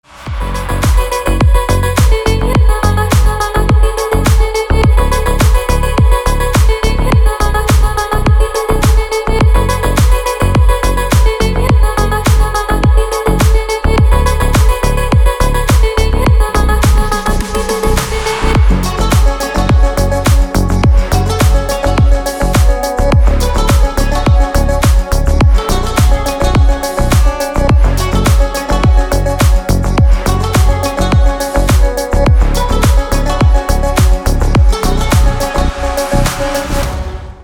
• Песня: Рингтон, нарезка